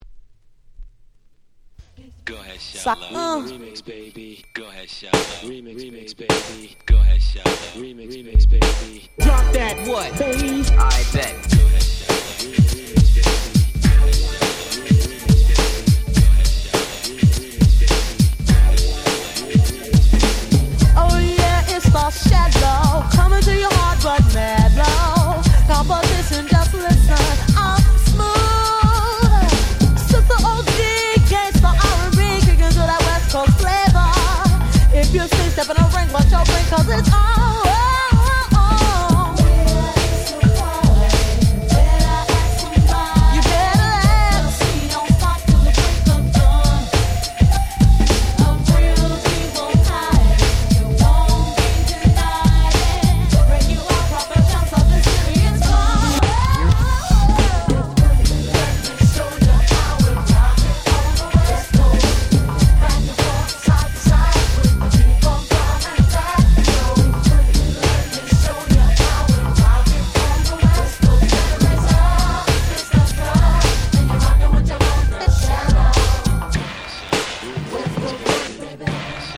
94' Nice West Coast R&B !!